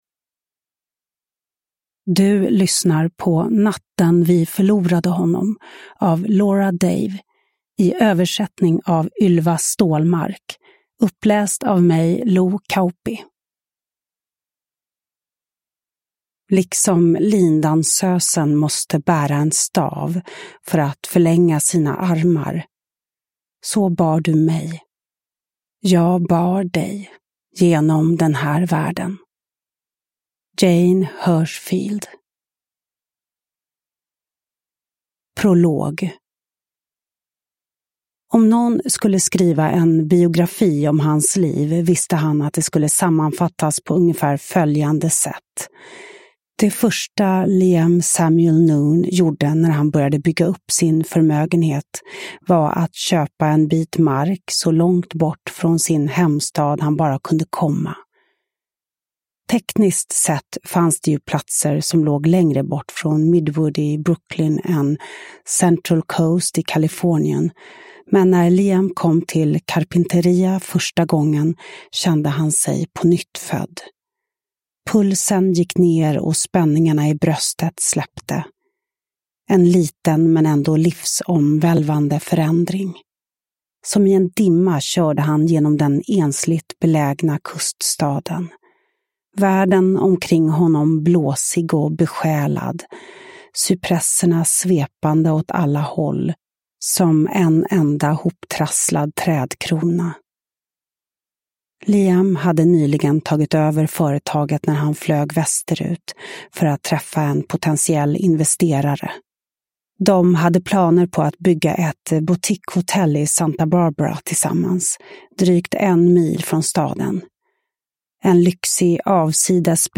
Uppläsare: Lo Kauppi
Ljudbok